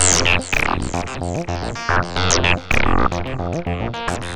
UR 303 acid bass 1 h.wav